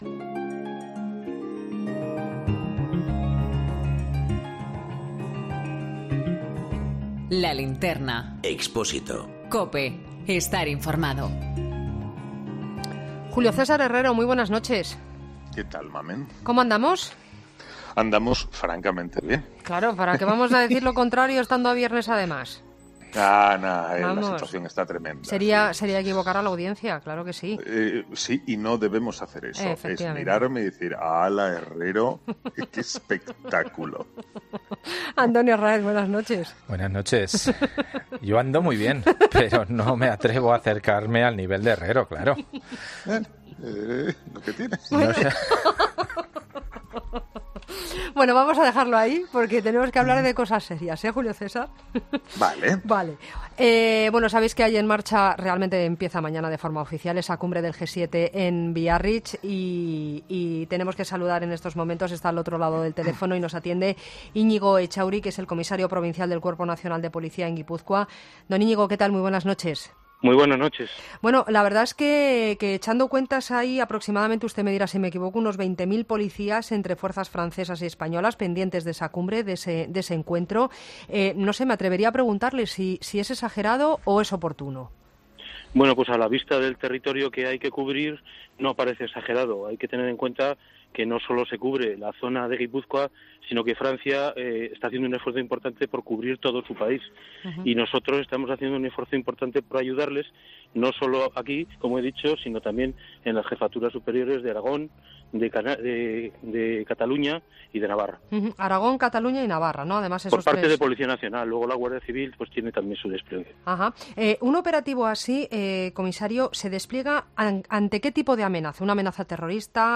El comisario provincial del Cuerpo Nacional de Policía en Guipúzcoa ha comentado cómo está siendo el operativo especial lanzado con motivo del G-7